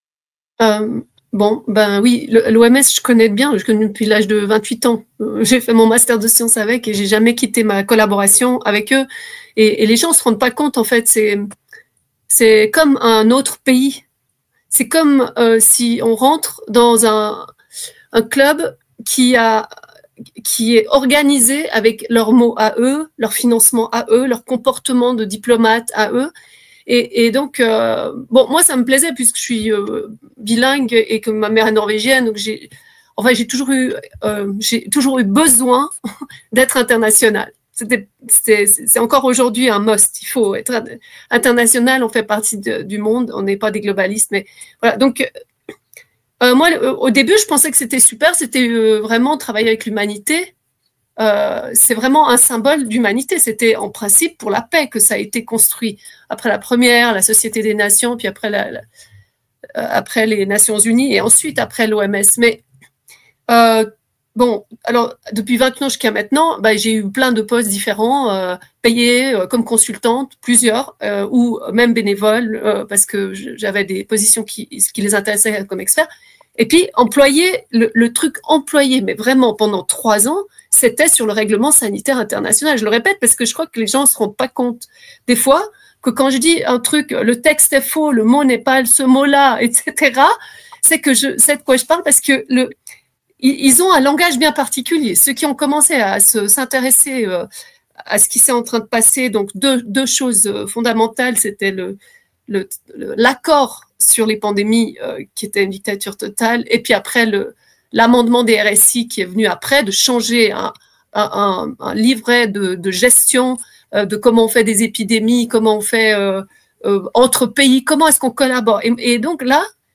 Interview de KLA TV lors de la manifestation contre l’OMS à Genève le 1er juin 2024: